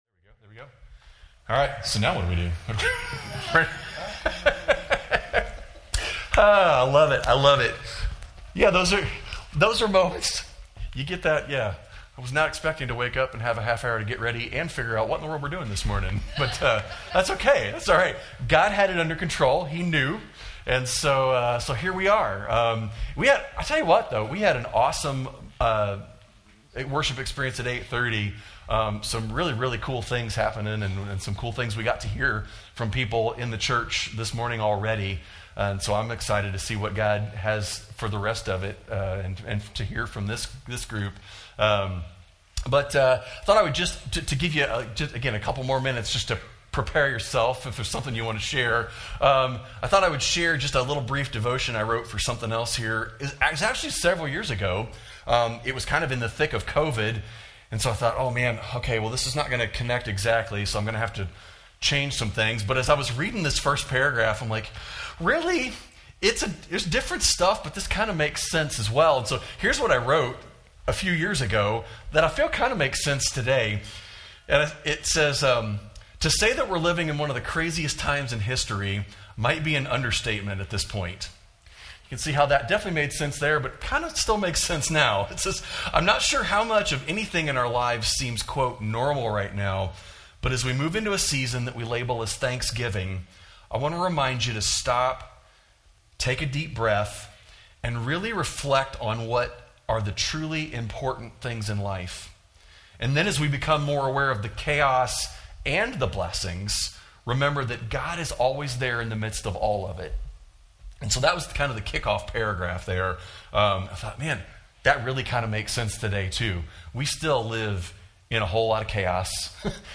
Gratitude Sunday (testimonies)